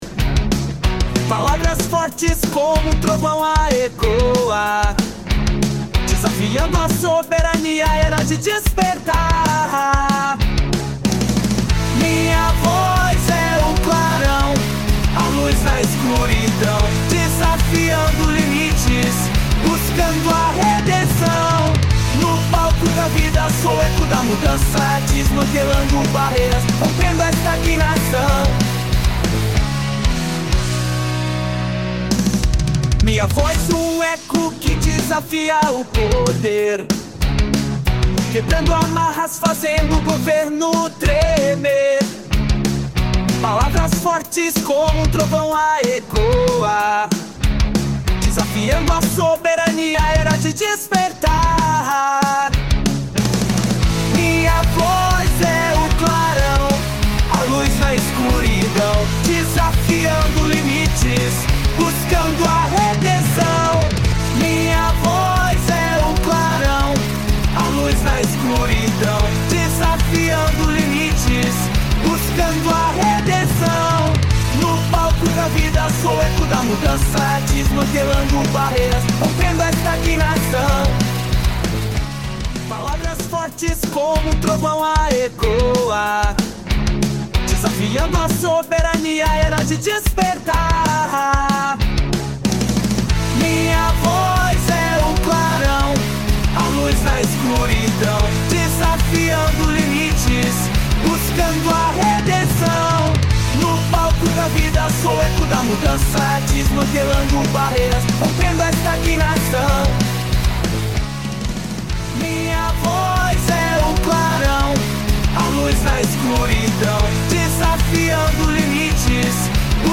Gênero Funk.